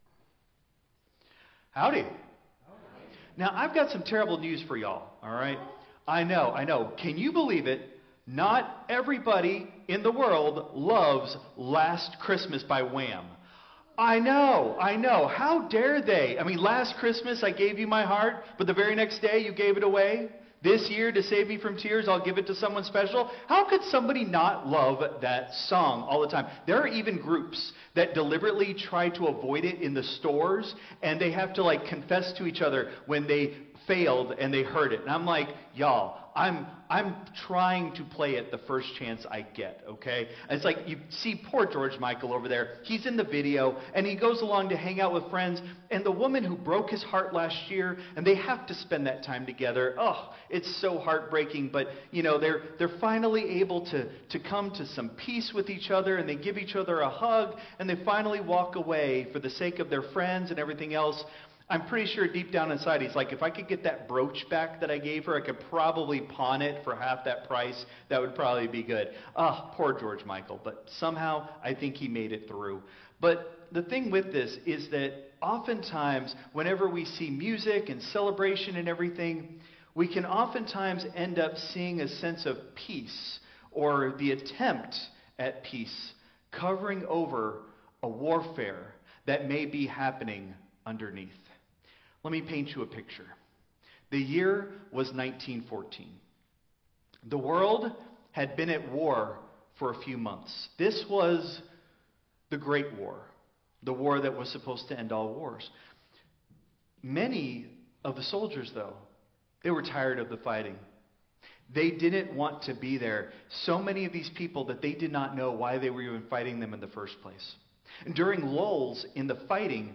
Christ Memorial Lutheran Church - Houston TX - CMLC 2024-12-08 Sermon (Contemporary)